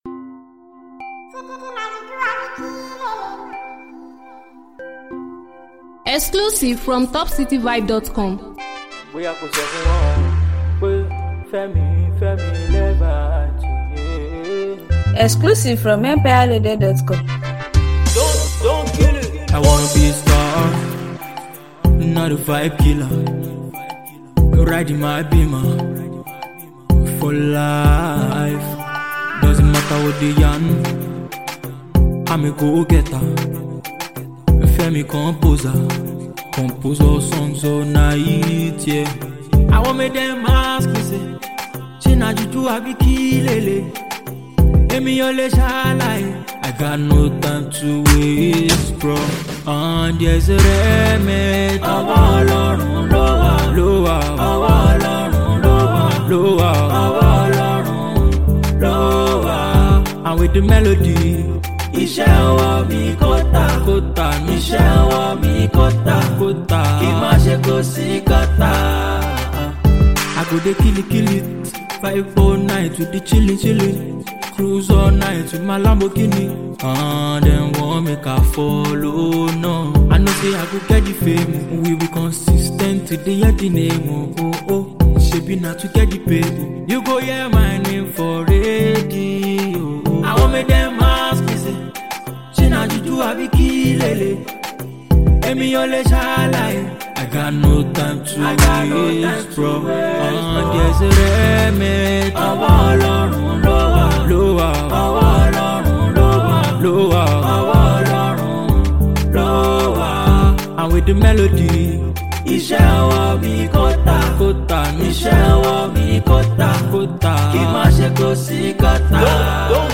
Gospel/Pop
Inspirational/Motivational/Pop/Gospel track